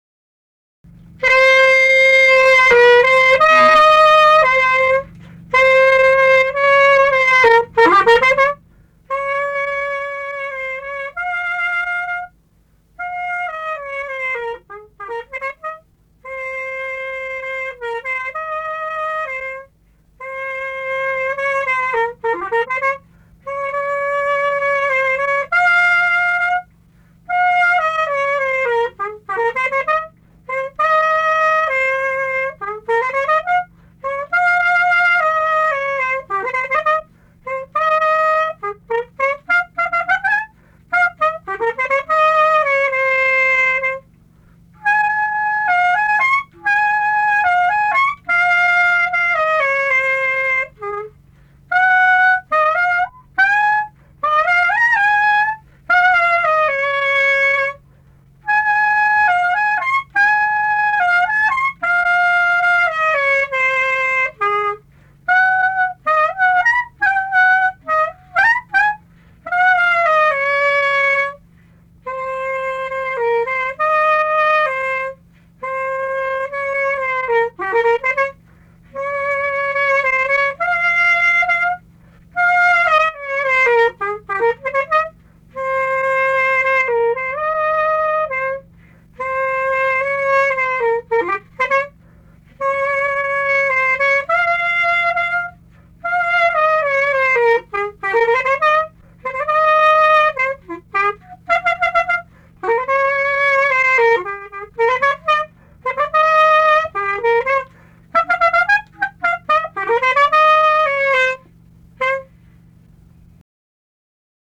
Dalykas, tema šokis
Atlikimo pubūdis instrumentinis
Instrumentas klarnetas